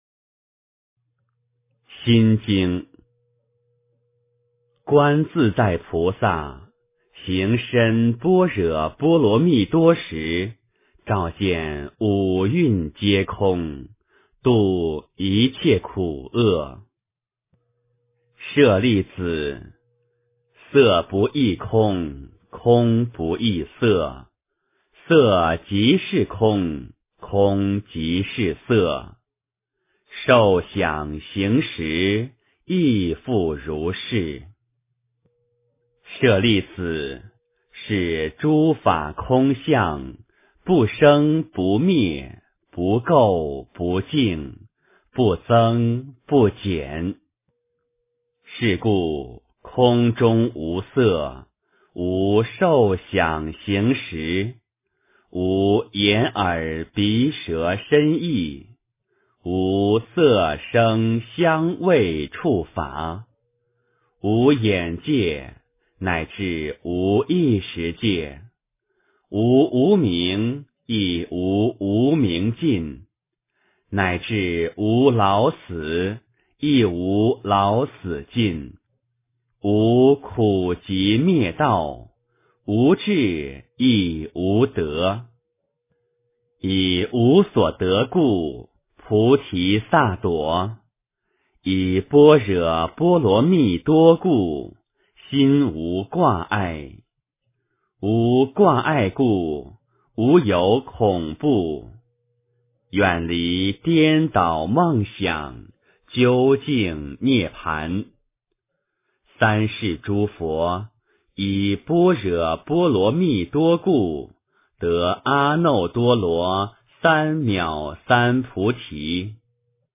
心经 诵经 心经--佛经 点我： 标签: 佛音 诵经 佛教音乐 返回列表 上一篇： 大宝积经 第112卷 下一篇： 金刚经 相关文章 普陀晨钟 普陀晨钟--群星...